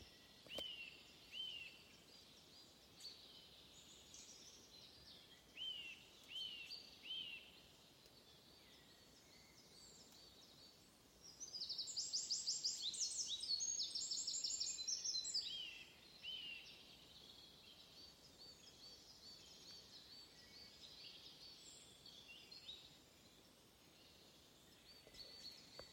Putni -> Zīlītes ->
Dzilnītis, Sitta europaea
Administratīvā teritorijaValkas novads